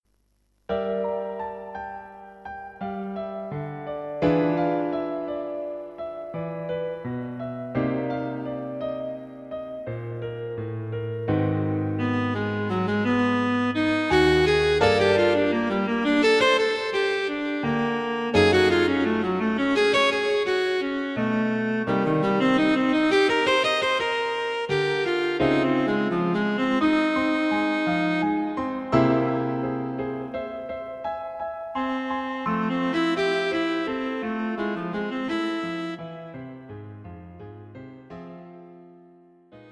Cello and Piano A very edgy piece in 5/4 time.